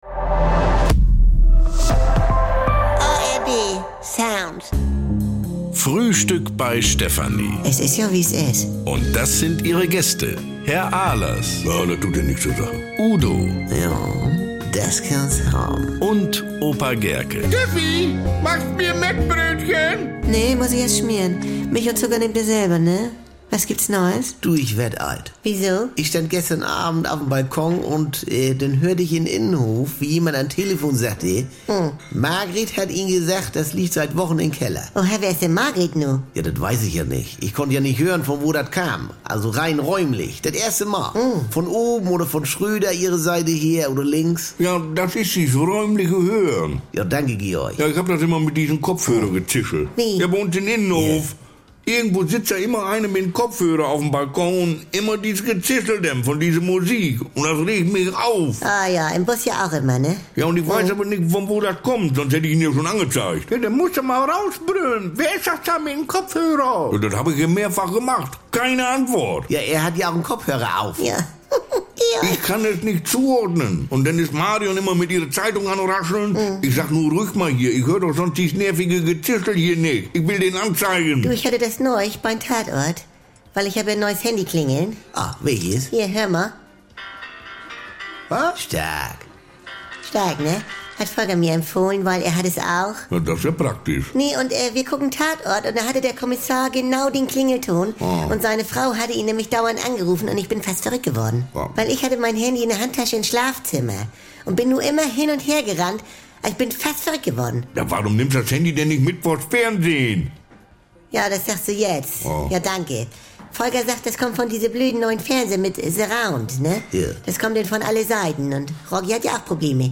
Unerhört: Zischelnde Kopfhörer-Laute, Fernseher mit Surround und